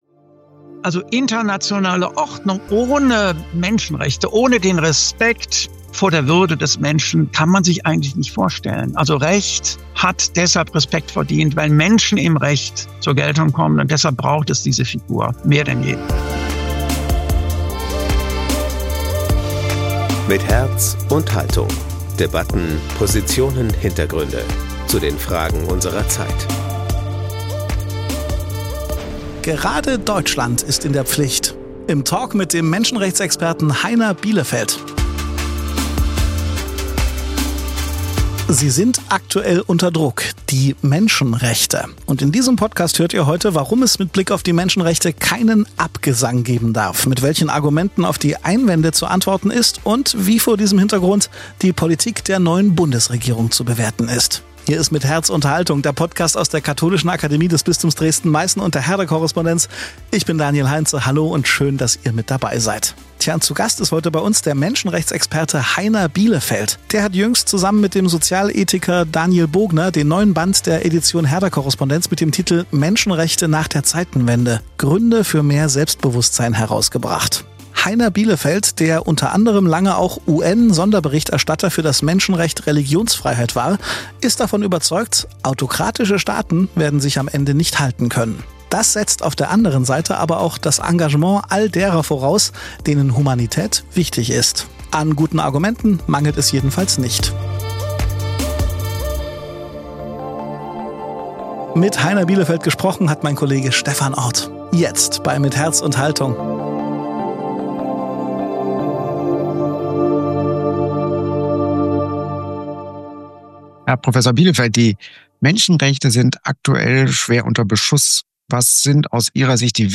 Aktuell sind die Menschenrechte unter Druck. Warum es mit Blick auf die Menschenrechte keinen Abgesang geben darf, darüber haben wir mit dem Menschenrechtsexperten Heiner Bielefeldt gesprochen.